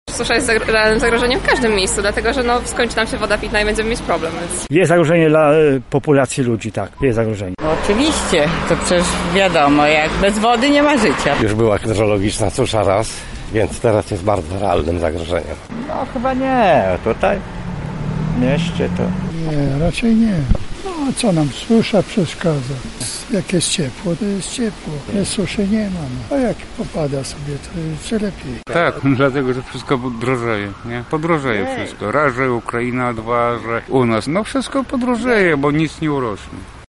Zapytaliśmy mieszkańców Lublina, czy według nich susza jest poważnym zagrożeniem dzisiaj:
sonda